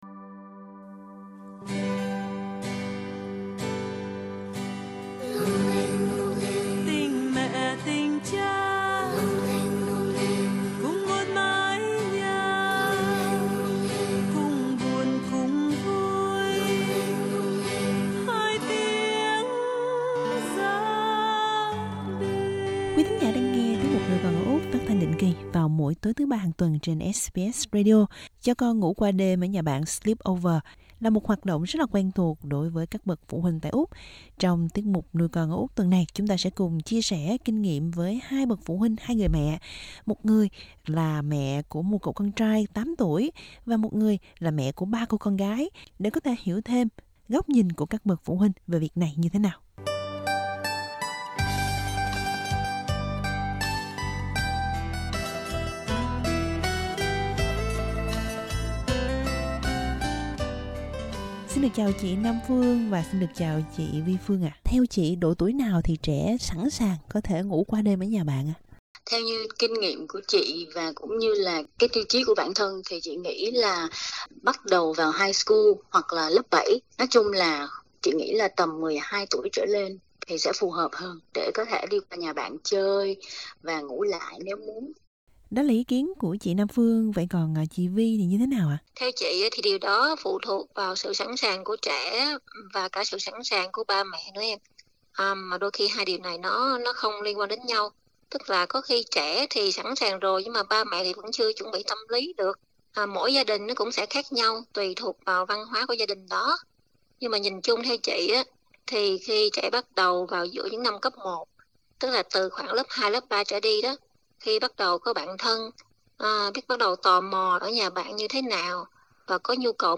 Hai bà mẹ Việt có con trai và con gái chia sẻ kinh nghiệm với SBS.